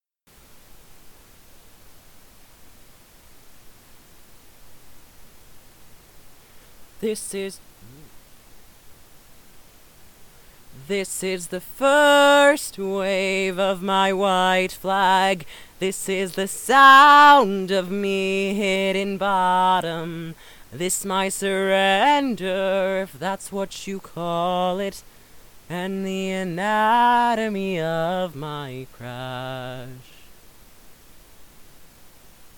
Just started recording... wondering what settings/things I need to change to make the vocal recording so much less harsh.
I attached a little sample of what my recordings are sounding like now.